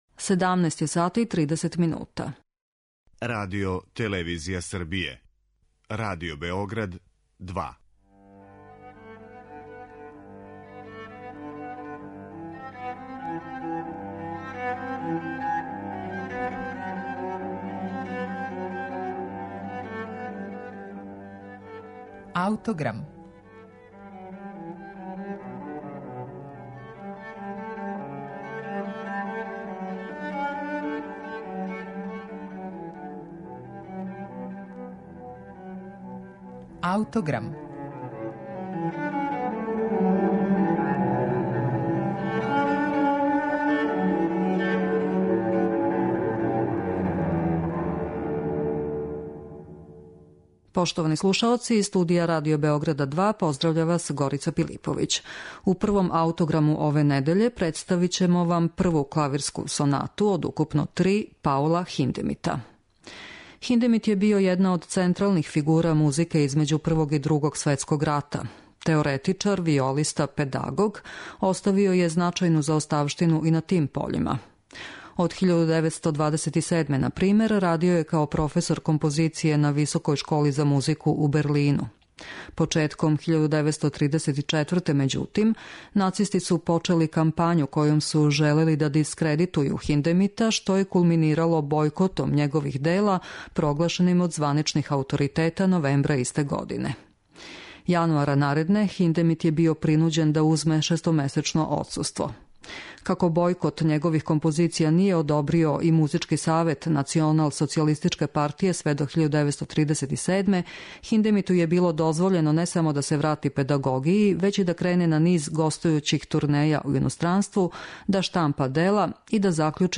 Прва клавирска соната Паула Хиндемита